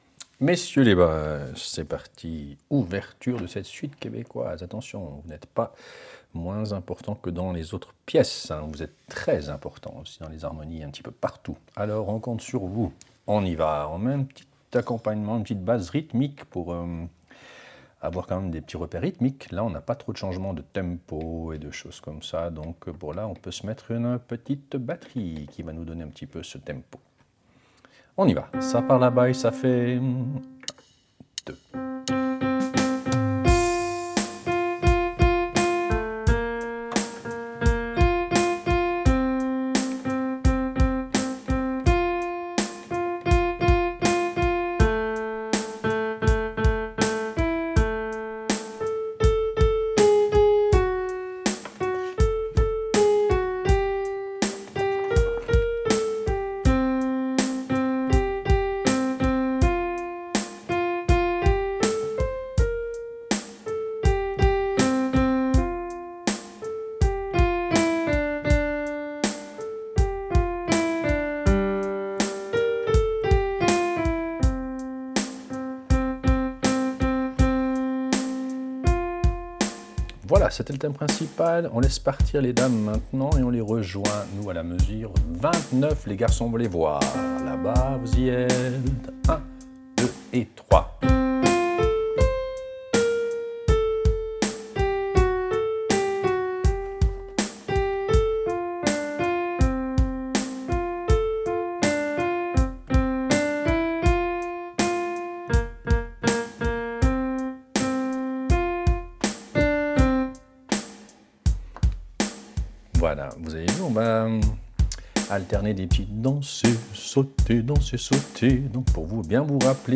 Répétition SATB4 par voix
Basse